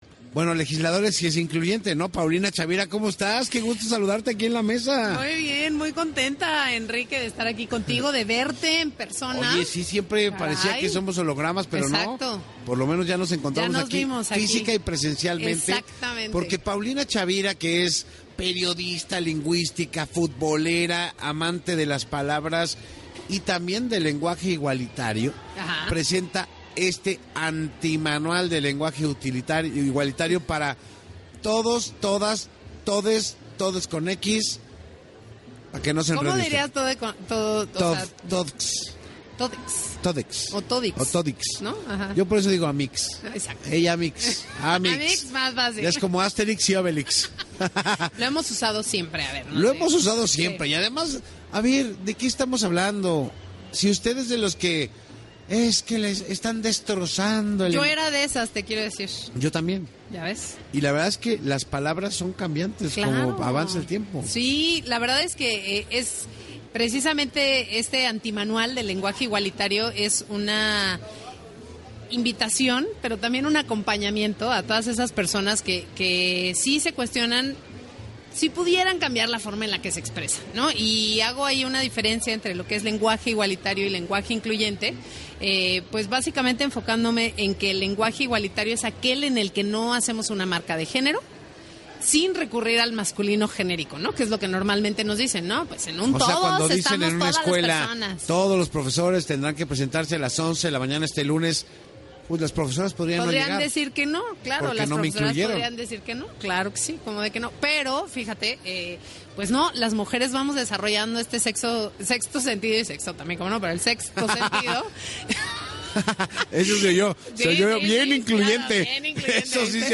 La periodista explica cómo se puede aprender que la lengua cambia constantemente